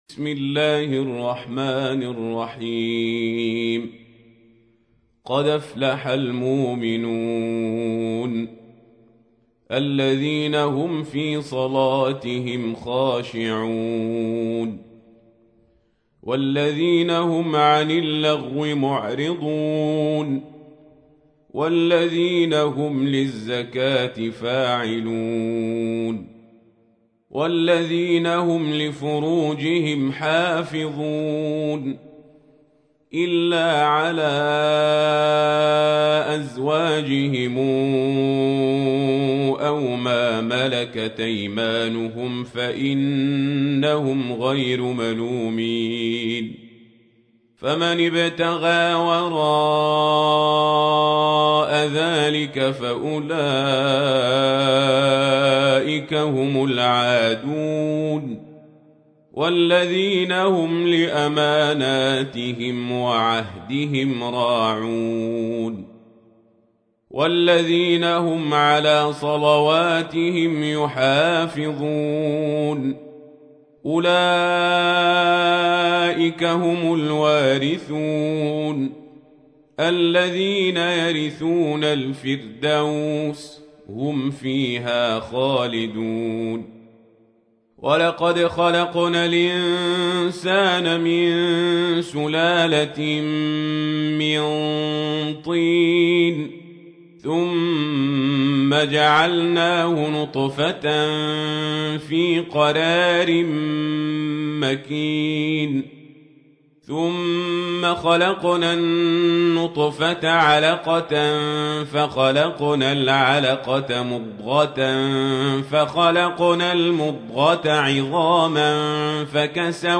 تحميل : 23. سورة المؤمنون / القارئ القزابري / القرآن الكريم / موقع يا حسين